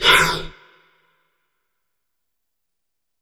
Heavy Breaths
BREATH2W-R.wav